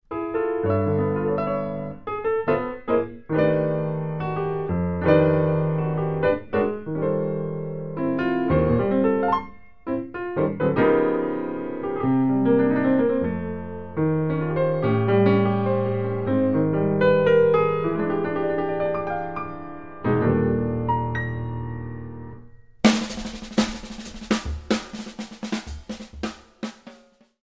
medium up swing